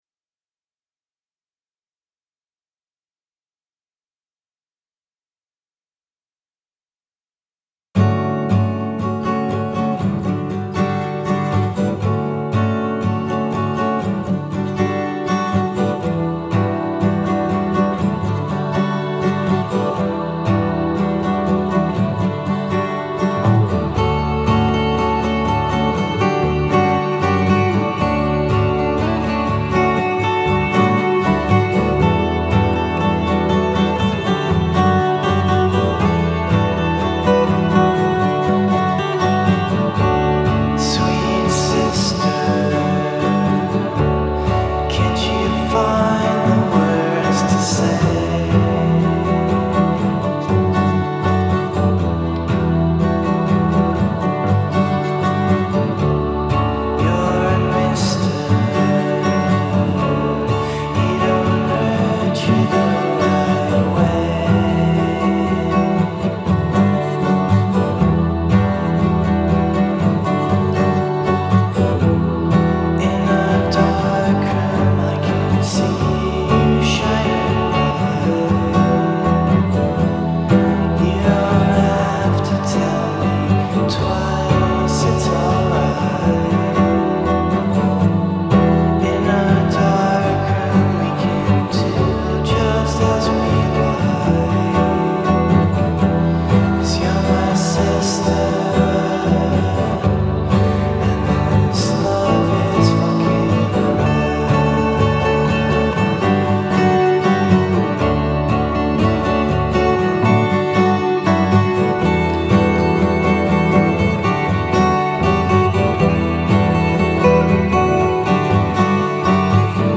la band francese
eterea cover